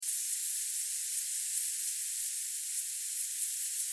دانلود آهنگ آب 41 از افکت صوتی طبیعت و محیط
جلوه های صوتی
دانلود صدای آب 41 از ساعد نیوز با لینک مستقیم و کیفیت بالا